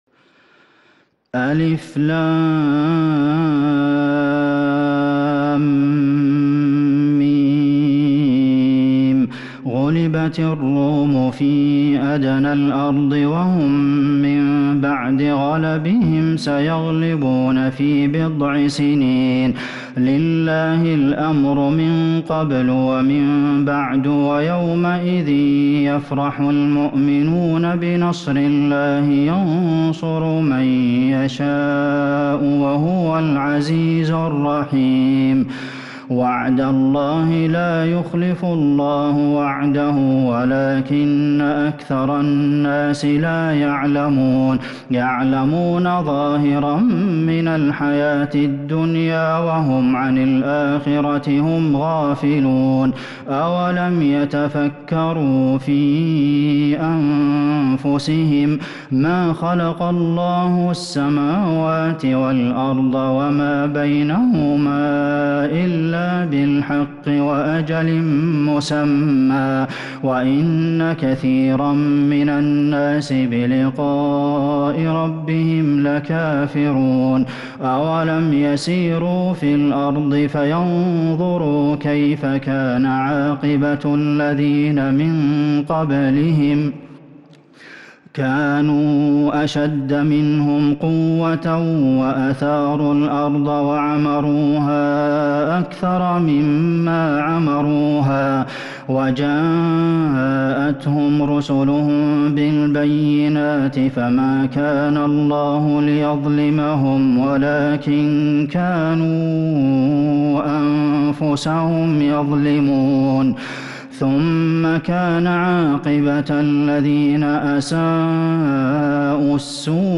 سورة الروم كاملة من تراويح الحرم النبوي 1442هـ > مصحف تراويح الحرم النبوي عام 1442هـ > المصحف - تلاوات الحرمين